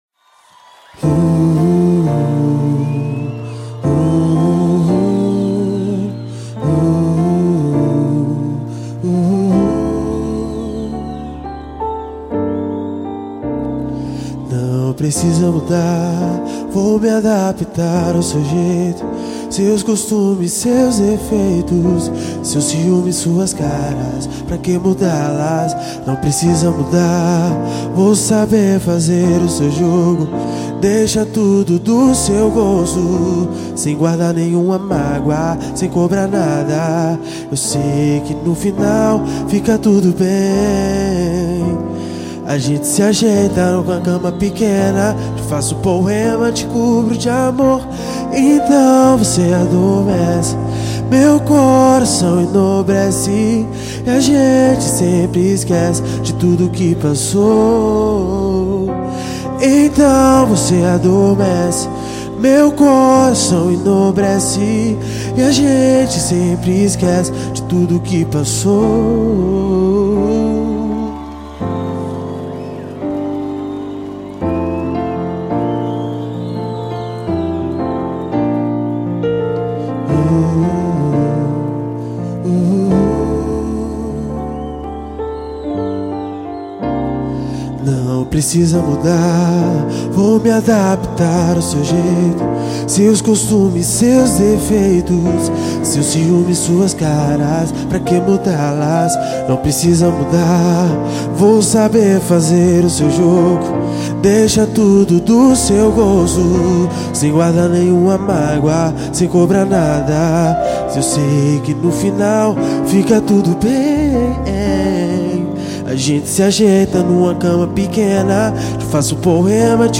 Cd promocional ao vivo venda proibida.